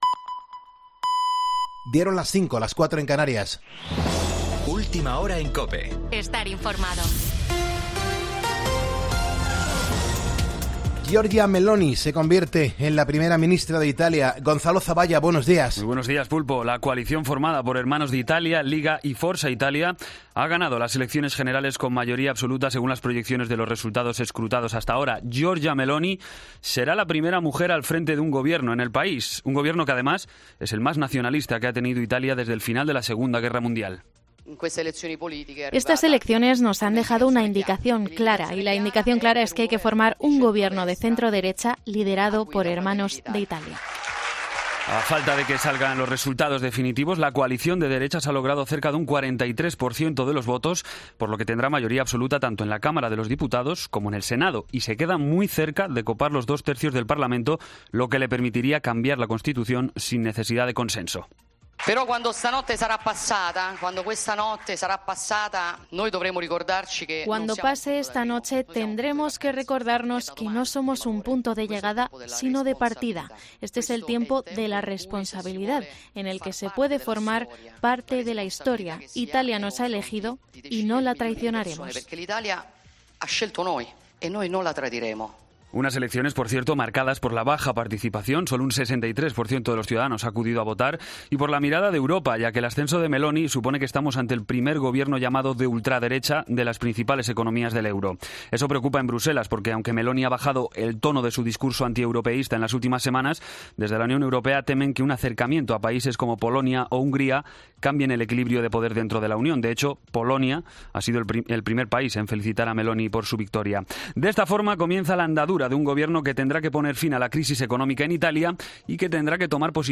Boletín de noticias COPE del 26 de septiembre a las 05:00 hora